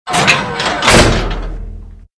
CHQ_VP_door_close.ogg